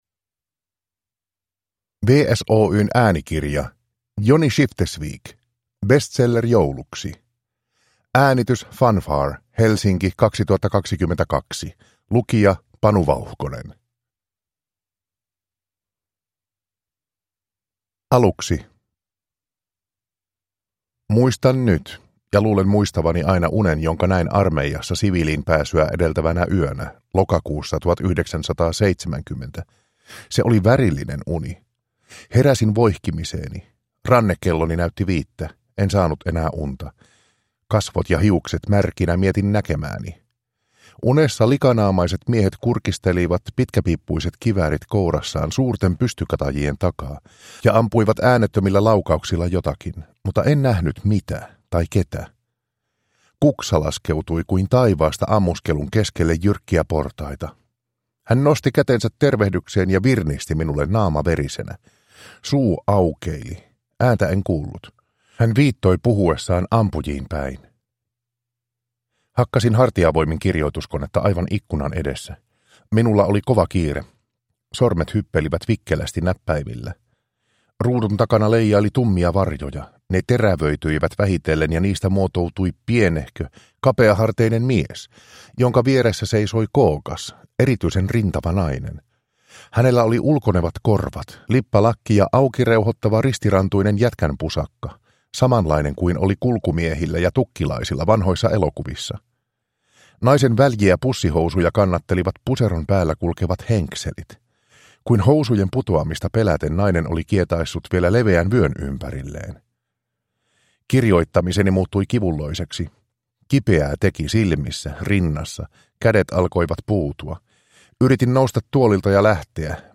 Bestseller jouluksi – Ljudbok – Laddas ner